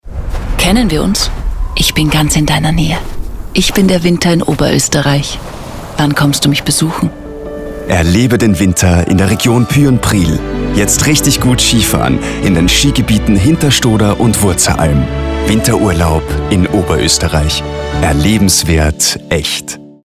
Hörfunk-Spot Urlaubsregion Pyhrn-Priel.